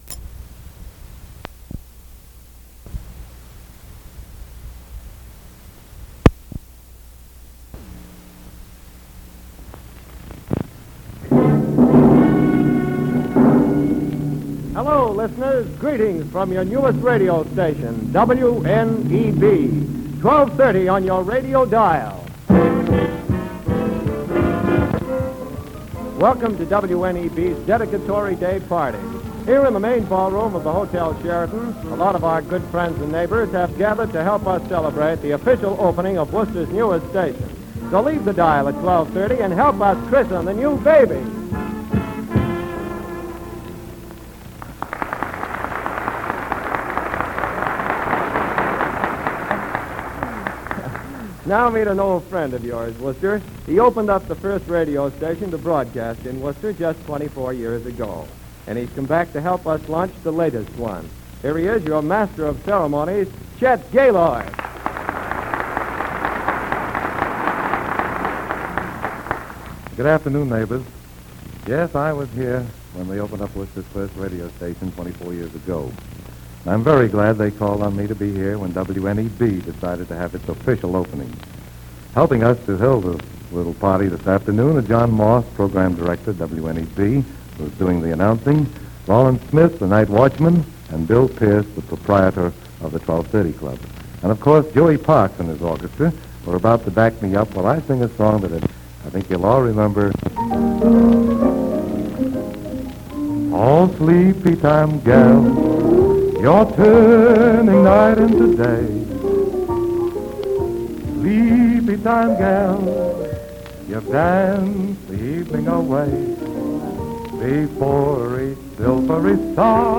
This is the opening broadcast from WNEB in Worcester on December 16, 1946.